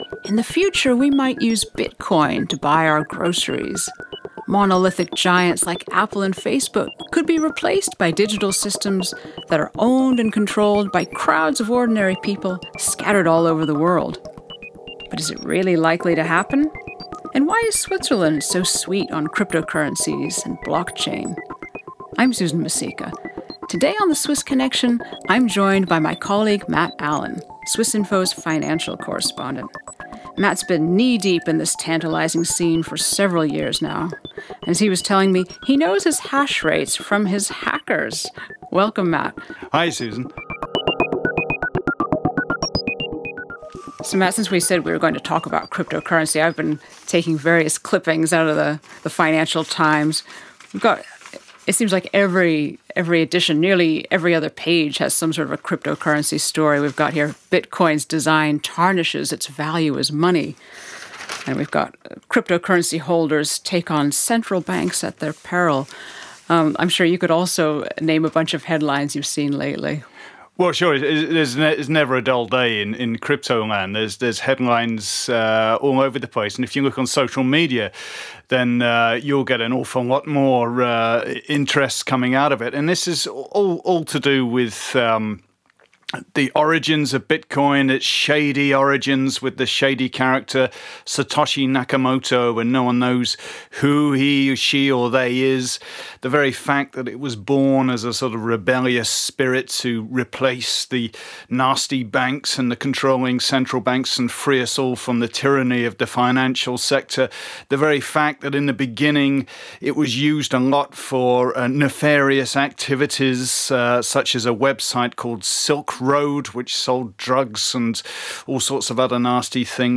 two people talking about cryptocurrency